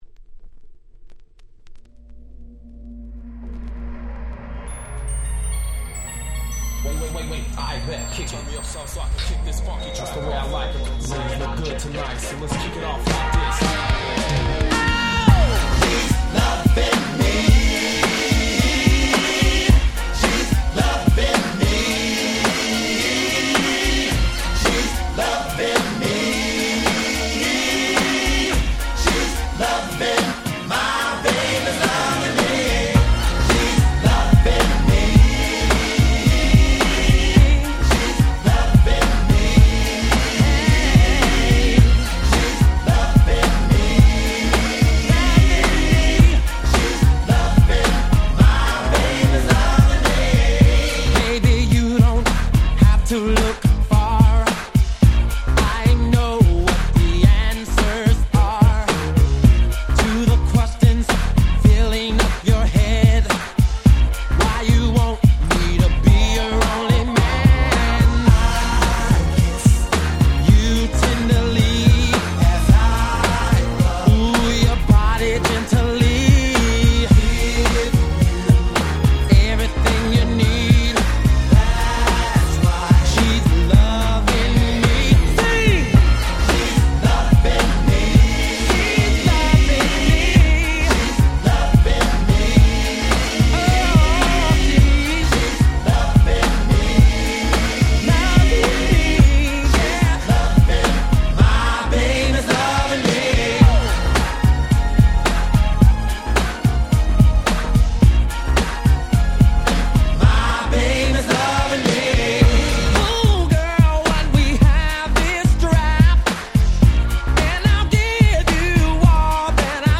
95’ Very Nice R&B !!